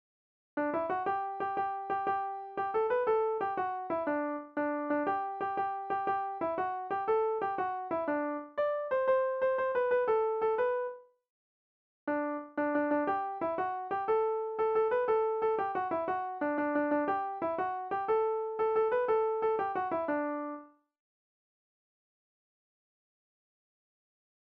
Genre énumérative